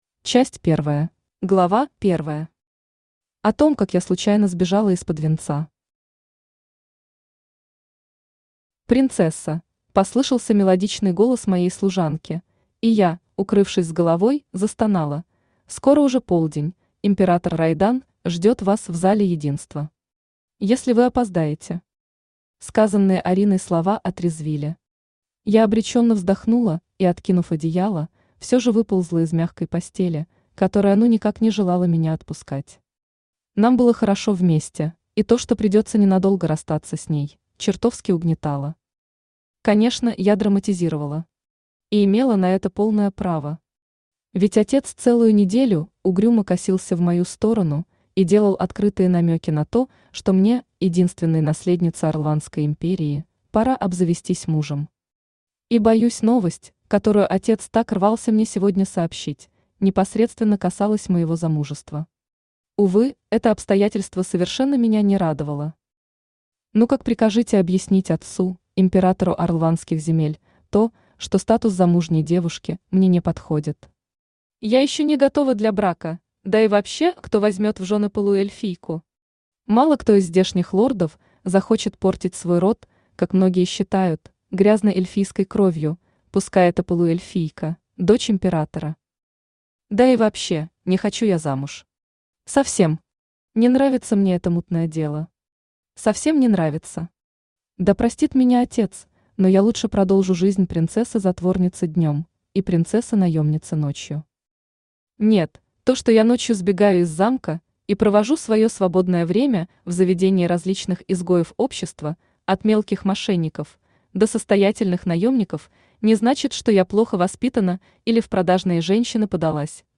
Аудиокнига Демон для меня. Сбежать и не влюбиться | Библиотека аудиокниг
Сбежать и не влюбиться Автор Casey (Кэйси Лисс) Liss Читает аудиокнигу Авточтец ЛитРес.